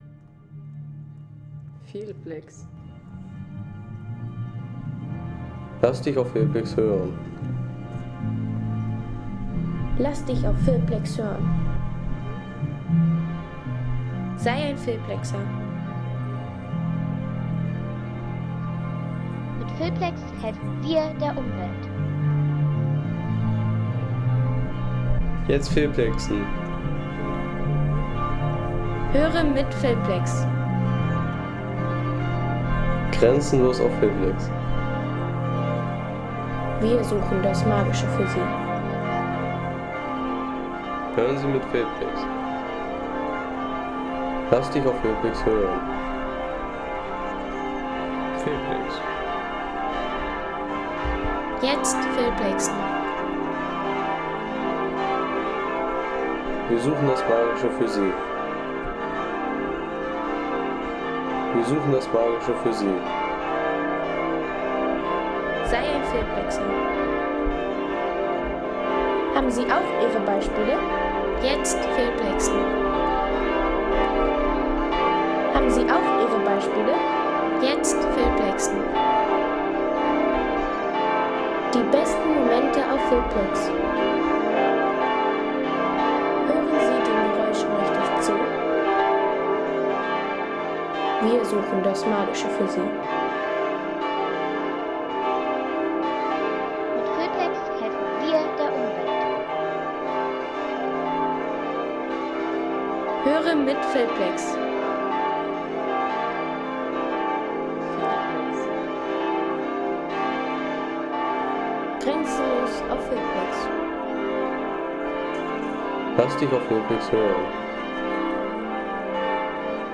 Der Klang der neuen Glocken der Dresdner Frauenkirche.